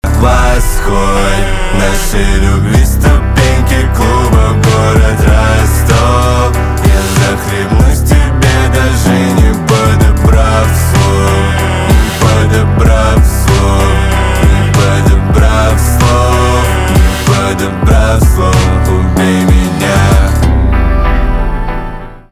русский рэп
битовые , басы , красивые , чувственные